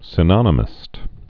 (sĭ-nŏnə-mĭst)